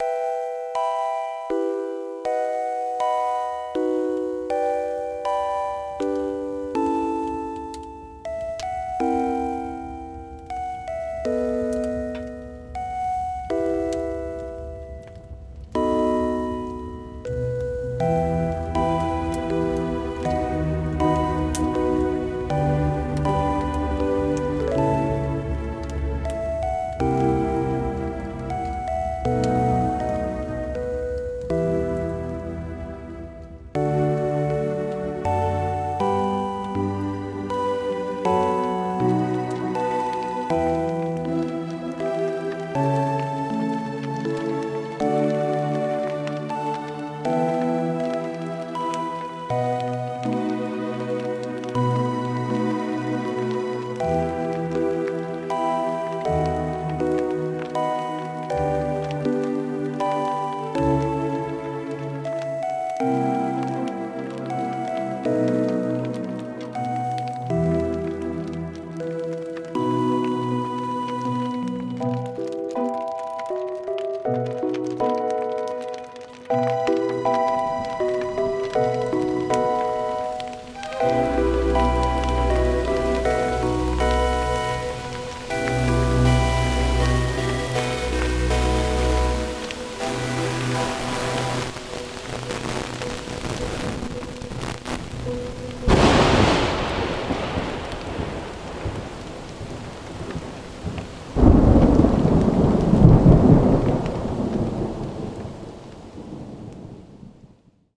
E miner. Starts with a sign wave playing a melody. A fire can be heard in the background. Strings eventually show up. As the piece progresses, the fire intensifies. The music is eventually burned to the point of being unrecognizable. A thunder clap sounds.